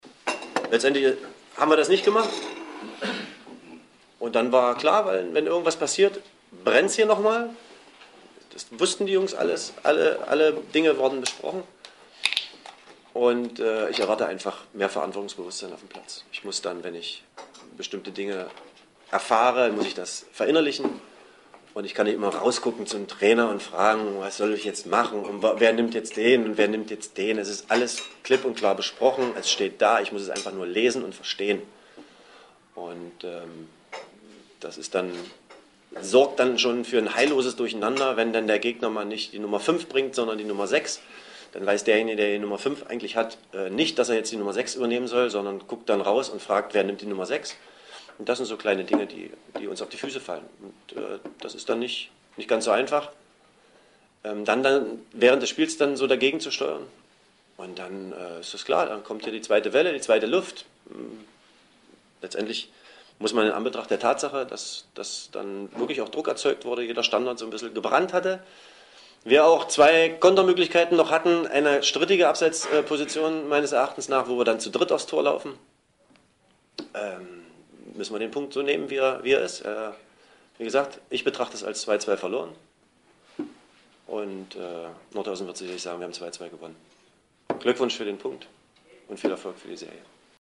Statements der Trainer